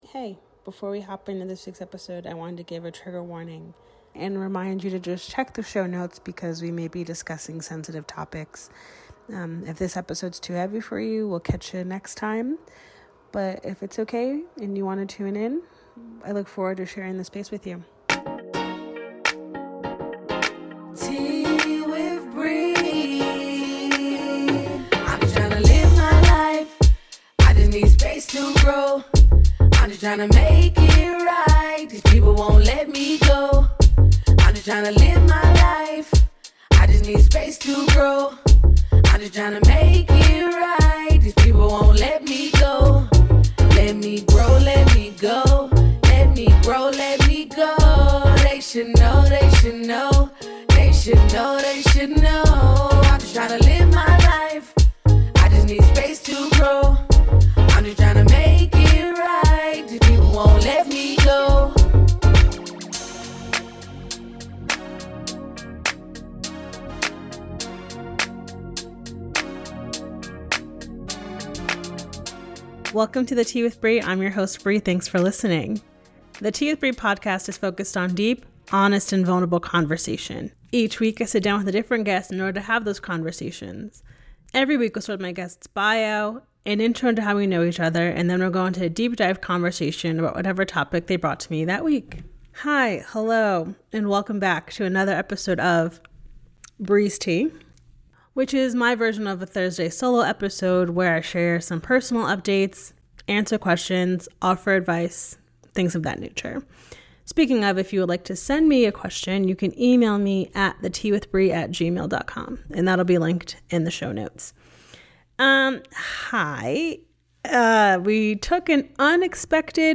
----- This podcast is recorded via Riverside FM.